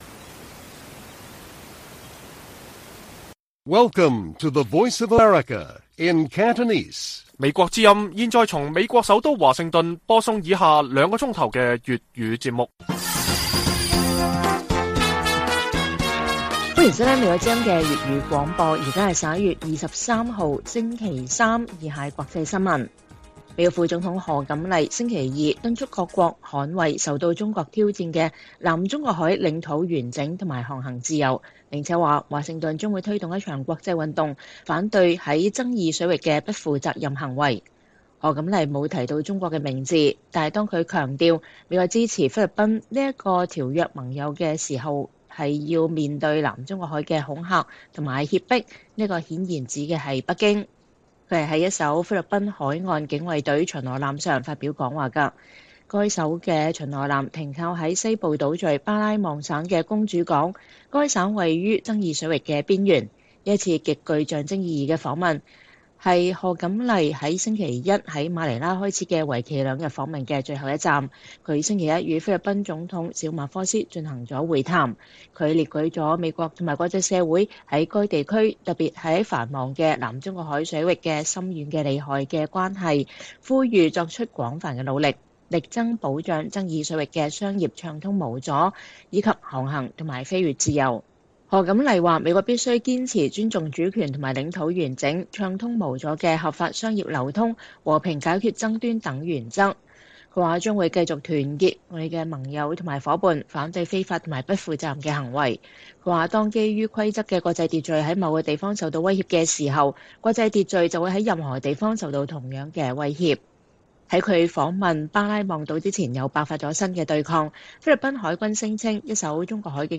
粵語新聞 晚上9-10點: 賀錦麗副總統敦促區域國家捍衛南中國海主權